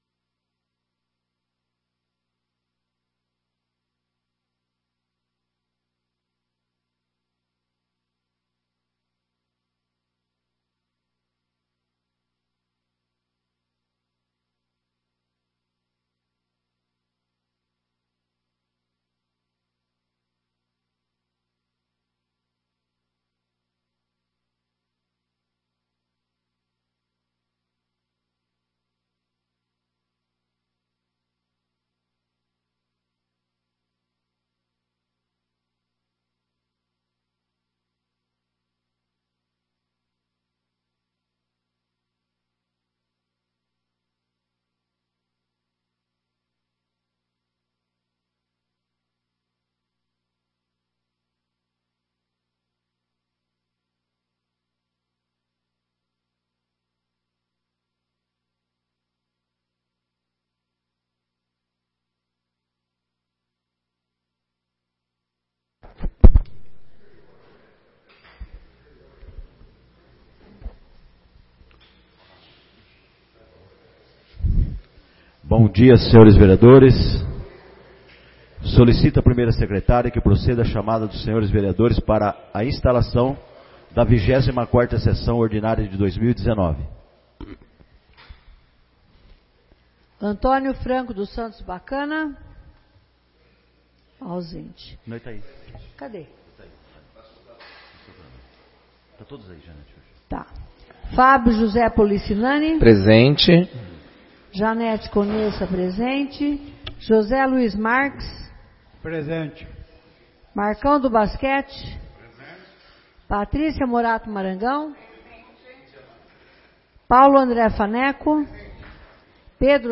24ª Sessão Ordinária de 2019